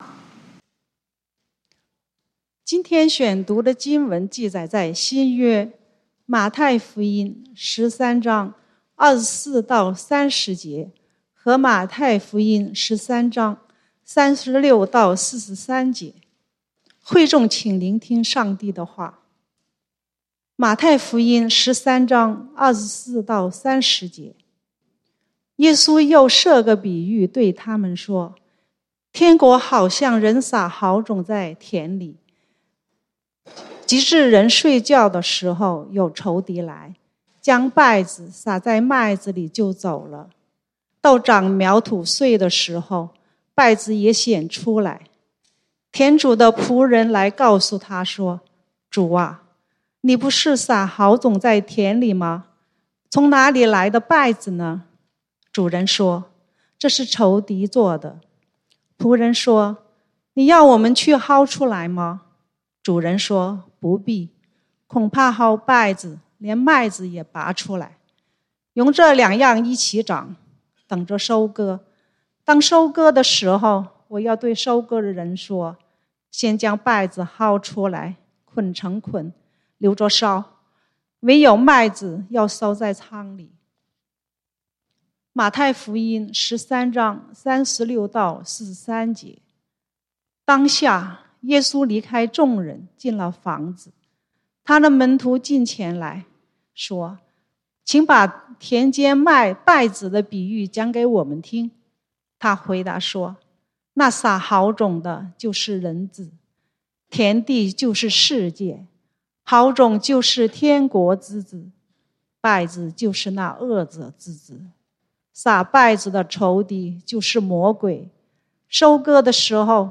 講道經文：馬太福音 Matthew 13:24-30, 36-43